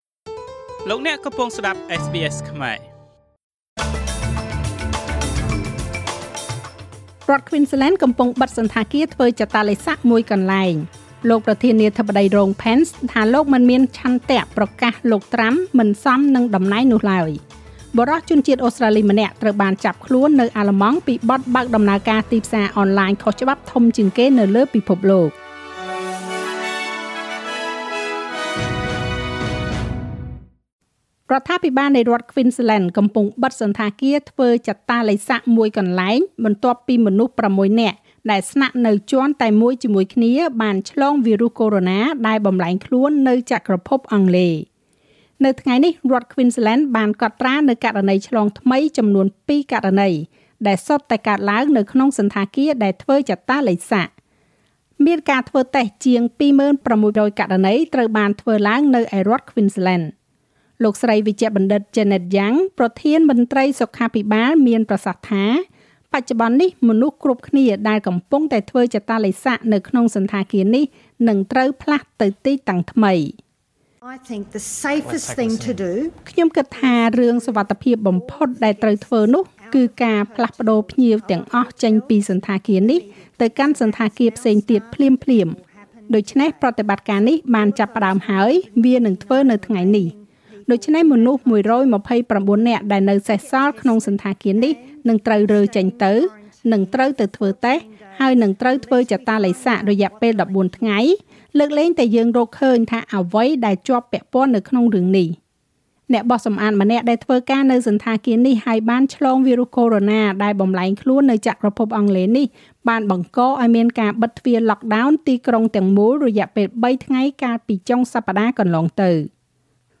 នាទីព័ត៌មានរបស់SBSខ្មែរ សម្រាប់ថ្ងៃពុធ ទី១៣ ខែមករា ឆ្នាំ២០២១។